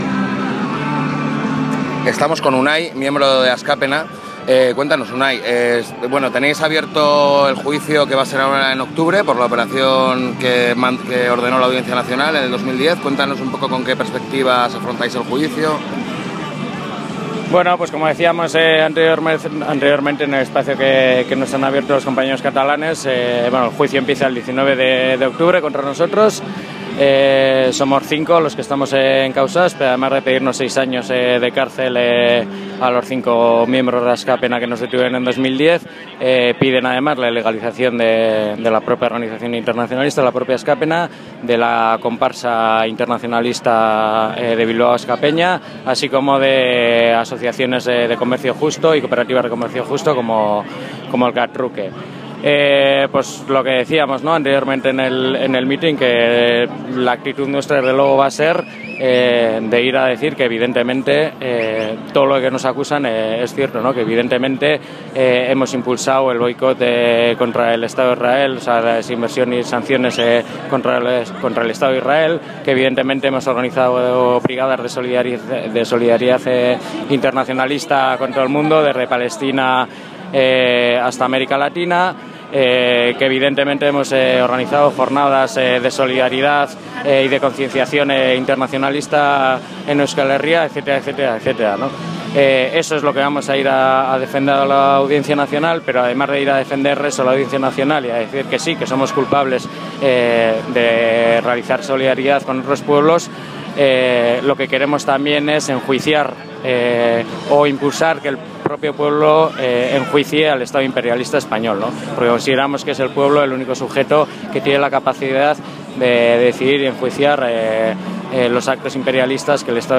La Haine entrevista